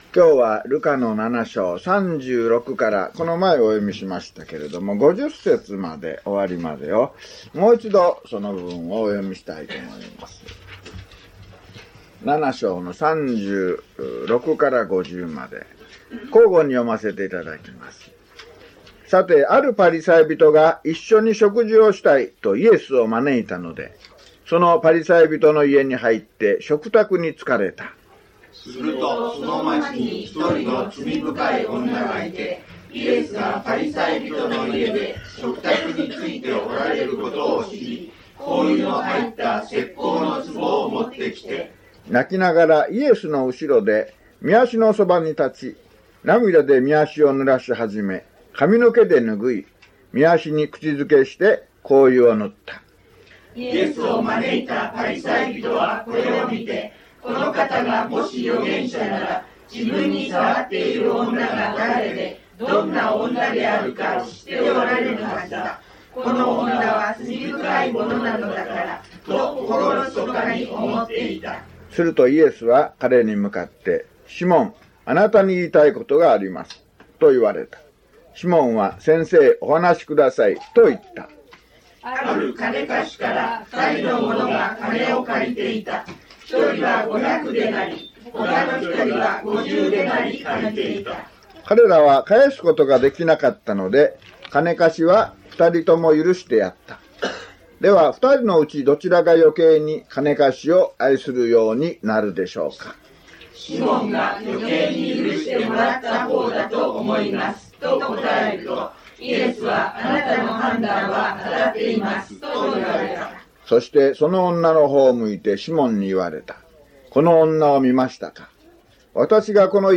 luke053mono.mp3